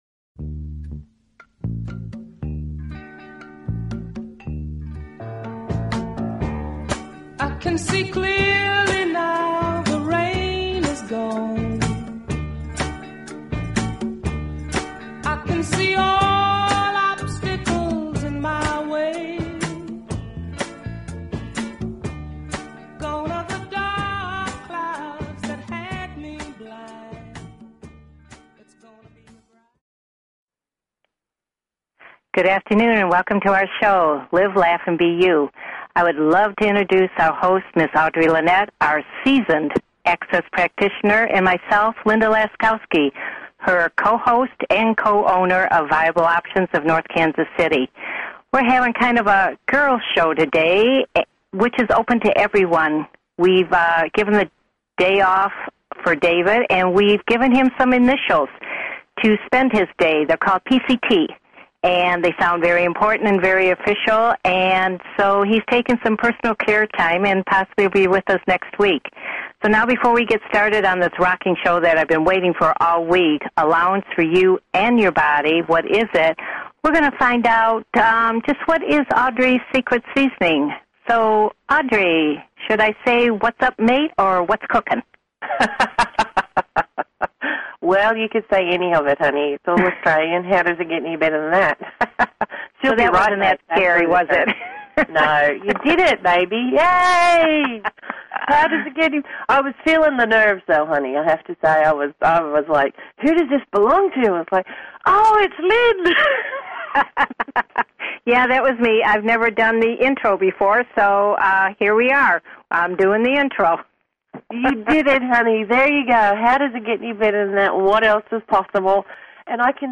This radio show will be sharing the tools offered by Access Consciousness and a wide variety of Alternative Health wisdom to help you the listener realize all the potential that you have as an Infinite Being!